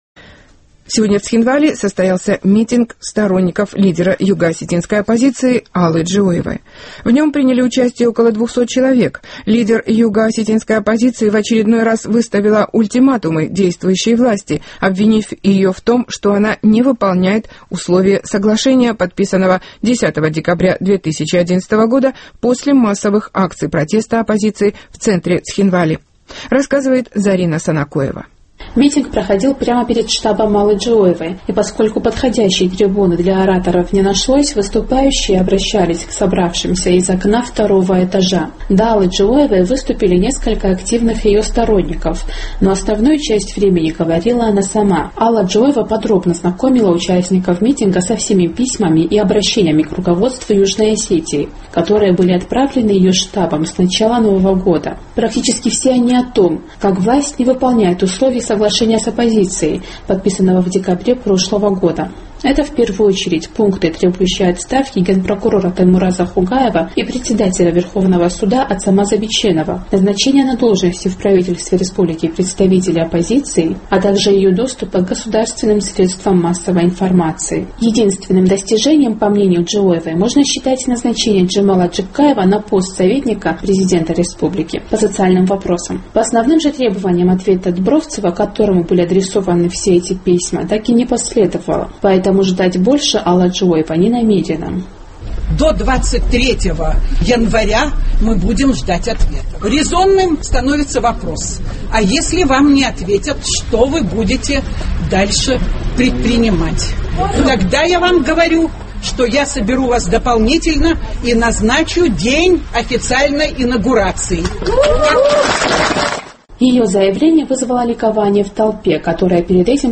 ЦХИНВАЛИ---Сегодня в Цхинвале состоялся митинг сторонников лидера югоосетинской оппозиции Аллы Джиоевой.
Поскольку подходящей трибуны для ораторов не нашлось, выступающие обращались к собравшимся из окна второго этажа.
Ее заявление вызвало ликование в толпе, которая перед этим постоянно прерывала выступление криками: «Что будем делать дальше?»